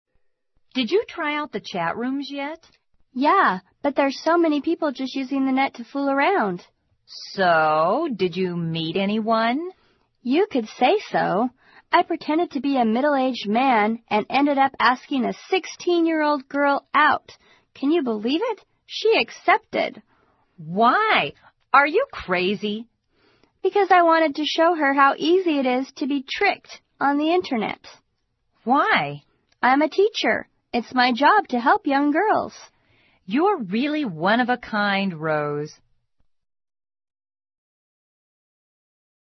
《网络社交口语对话》收集了众多关于网络社交的口语对话，对提高你的口语大有用处，值得你收藏。